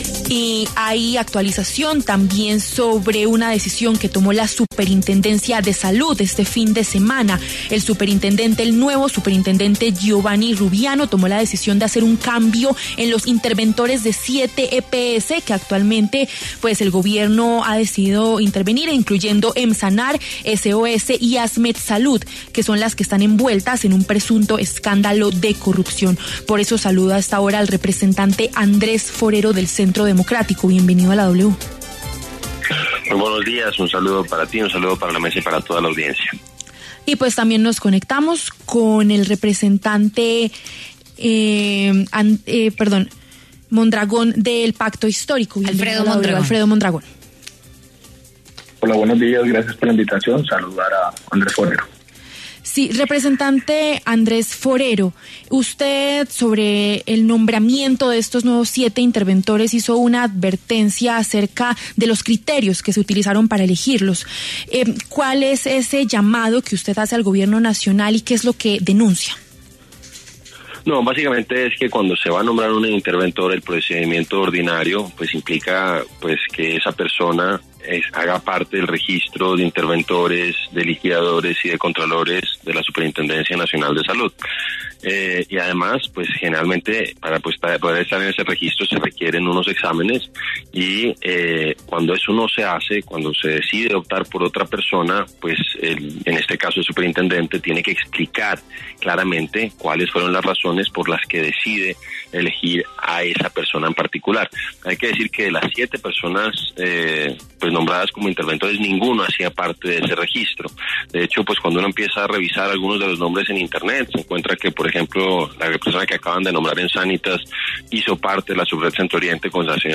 Los congresistas Andrés Forero y Alfredo Mondragón hablaron en W Fin de Semana de la decisión de la Supersalud de nombrar a nuevos agentes interventores para Sanitas, Nueva EPS, Savia Salud EPS, Asmet Salud, Emssanar EPS, Famisanar y Servicio Occidental de Salud S.O.S.